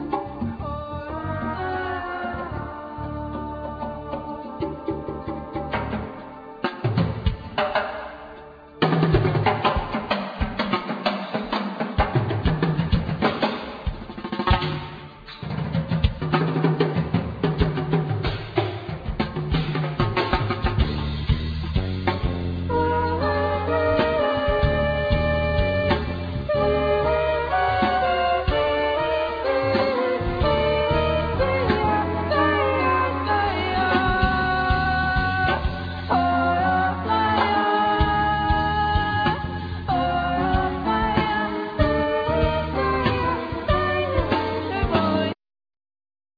Trumpet
Piano & Keyboard
Basses
Percussion,Drums,Voice